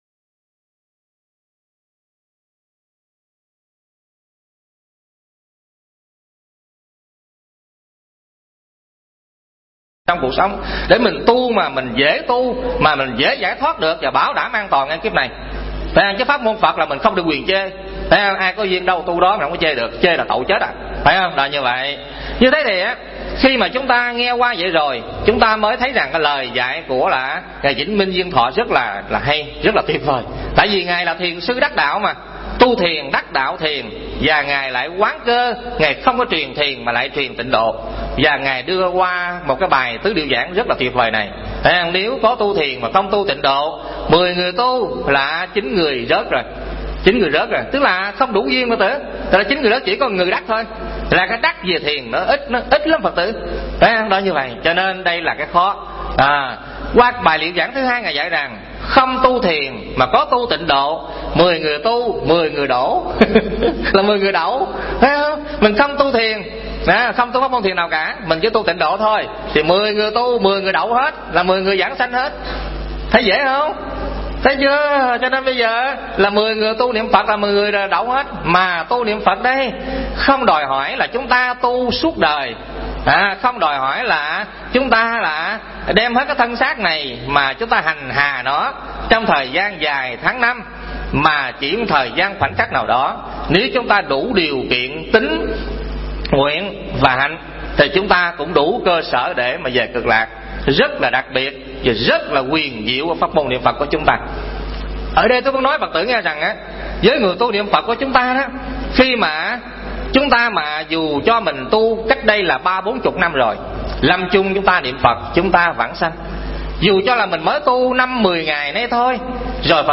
Tải mp3 Thuyết Pháp Nghe Hiểu Và Niệm Phật Phần 2